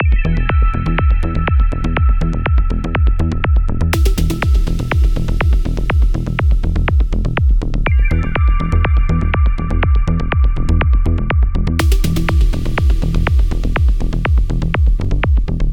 Такой прием характерен для full-on psytrance.
Пример (собрал только что для наглядности сравнения монотонной бас-линии с монотонной же, но некоторые ноты выведены на октаву выше):
Сразу возникает желание закрыть, на 5..8 секунде уже совсем надоедает, однообразно слишком, нет развития.
Это демонстрационный пример.